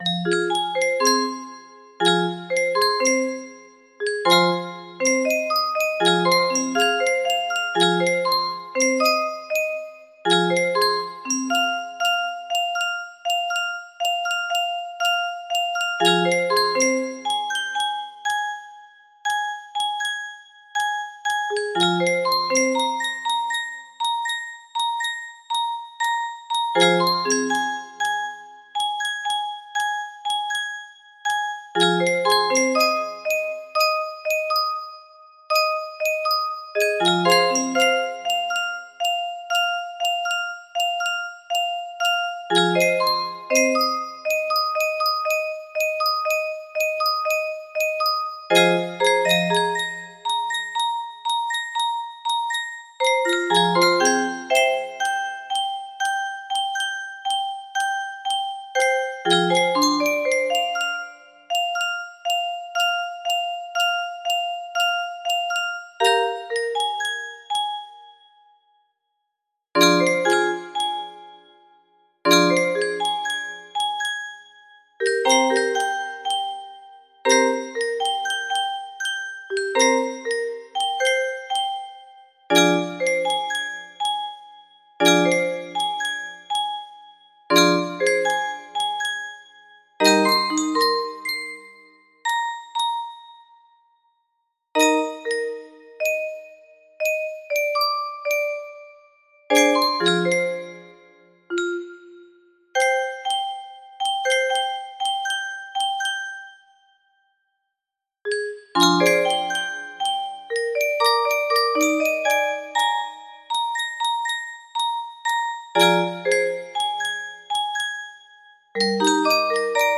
A Sanctuary of Solace music box melody
A little diddy I composed on my midi keyboard this morning.